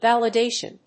音節val・i・da・tion 発音記号・読み方
/v`ælədéɪʃən(米国英語), ˌvælʌˈdeɪʃʌn(英国英語)/